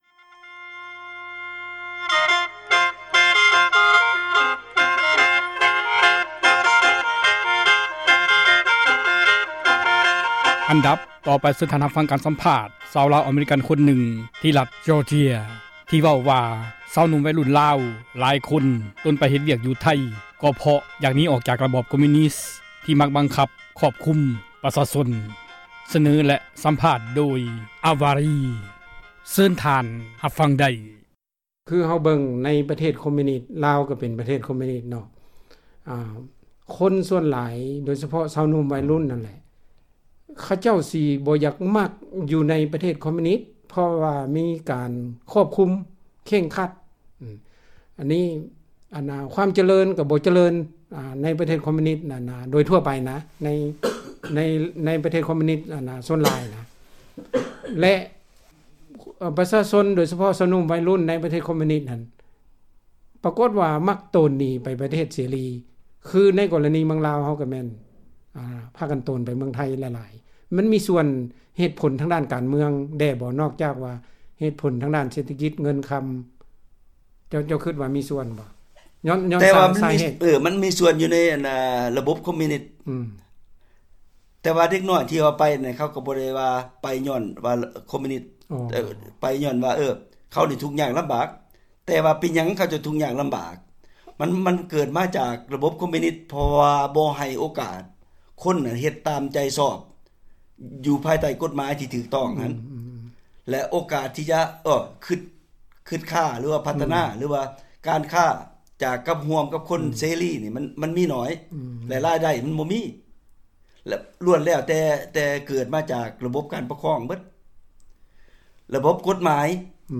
ການ ສຳພາດ ຊາວລາວ ອະເມຣິກັນ ຄົນນຶ່ງ ທີ່ຣັດ Georgia ທີ່ ເວົ້າວ່າ ຊາວໜຸ່ມ ໄວລຸ້ນ ລາວ ເປັນ ຈໍານວນ ຫລວງຫລາຍ ໂຕນໄປ ເຮັດວຽກ ຢູ່ໄທ ກໍເພາະ ຢາກ ໜີ ຈາກ ຣະບອບ ”ຄອມມຸຍນີສ” ທີ່ ມັກ ບັງຄັບ ຄວບຄຸມ ປະຊາຊົນ. ສເນີ ແລະ ສຳພາດ ໂດຍ